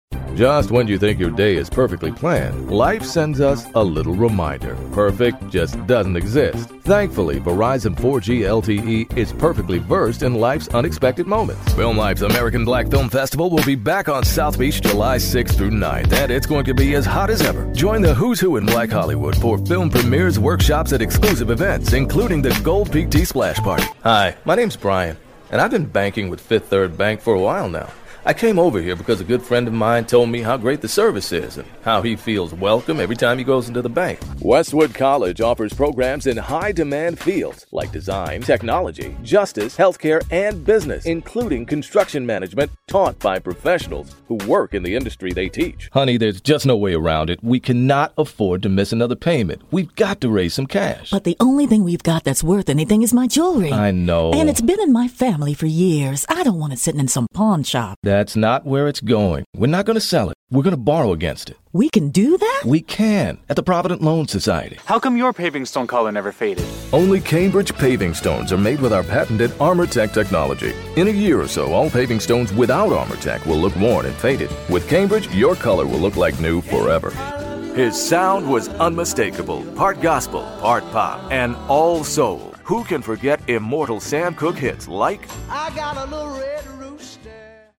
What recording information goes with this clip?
Studio & Services: Professional home studio providing broadcast-quality audio with fast turnaround.